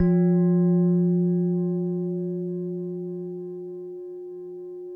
WHINE  F1 -R.wav